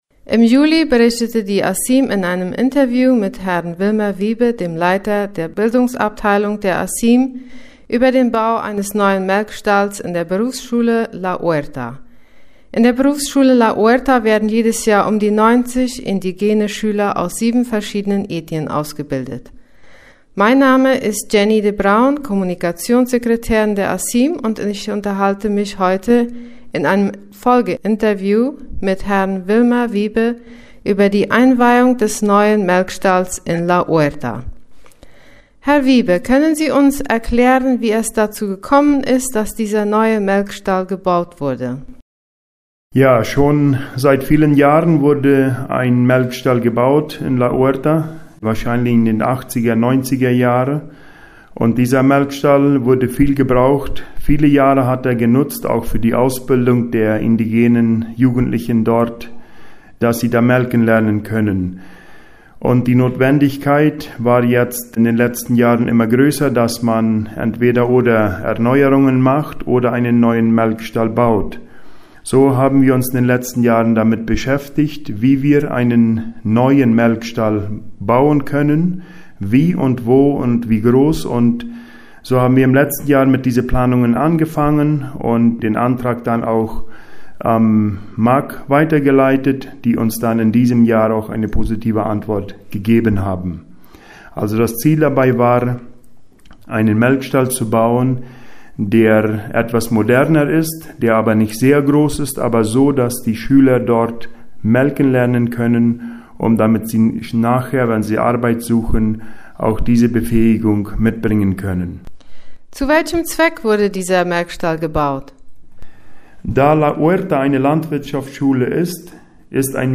2023-11-18_La Huerta Einweihungsfeier Melkstall